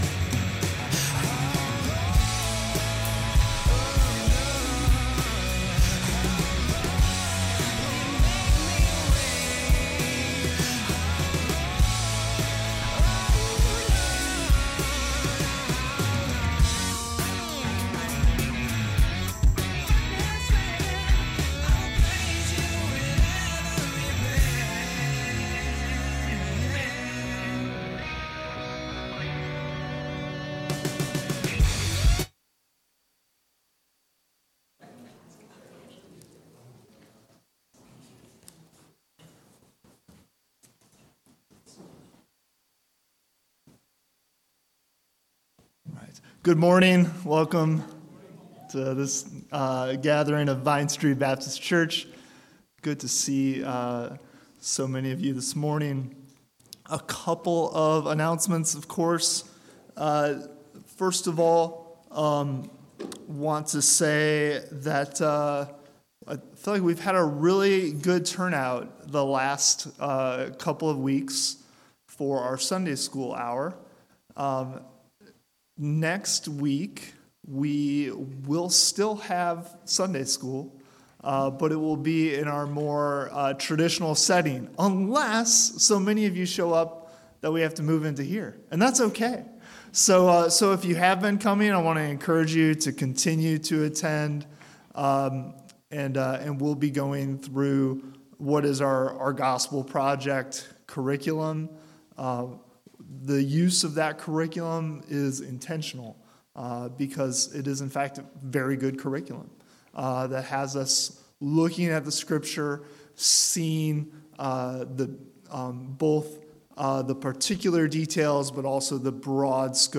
A video of today’s service can be seen on the Vine Street Baptist Church Facebook Page.
February 26 Worship Audio – Full Service